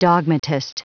Prononciation du mot dogmatist en anglais (fichier audio)
Prononciation du mot : dogmatist